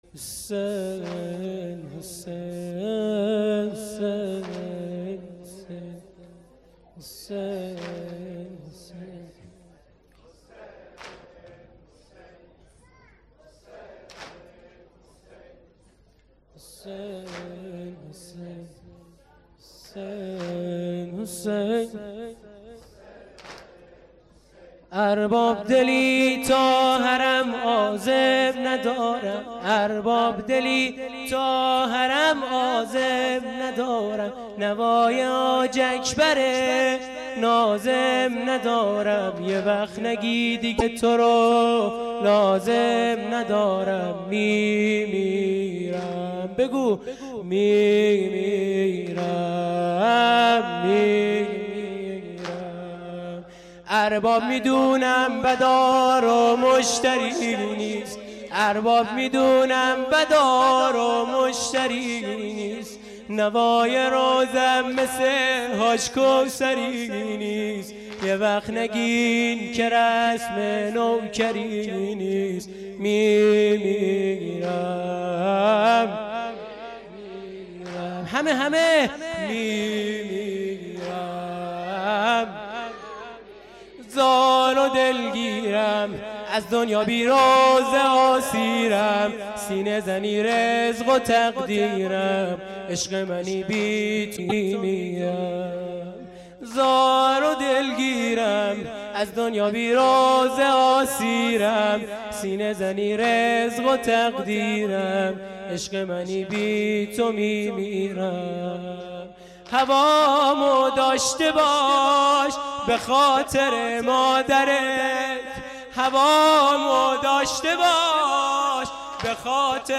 سینه زنی واحد
• مداحی
شب10-محرم93-هیئت-ثارالله-علیه-السلام-سینه-زنی-واحد.mp3